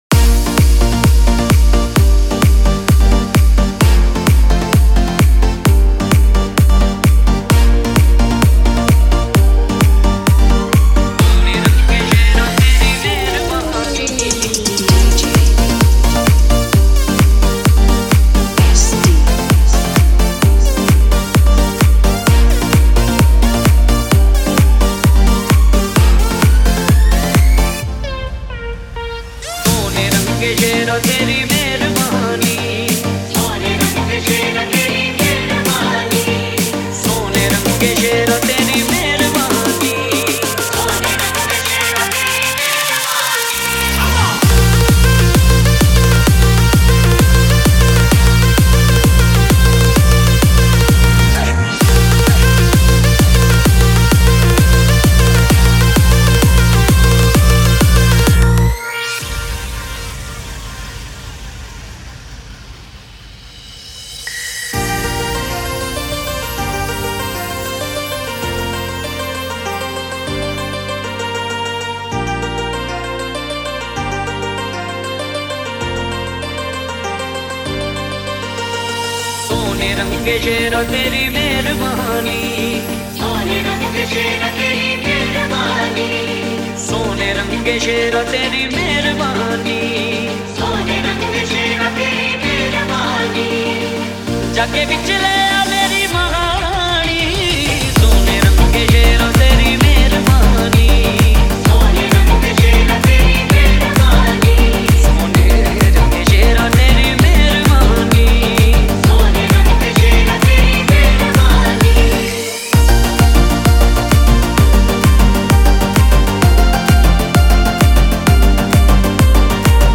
Bhakti DJ Remix Songs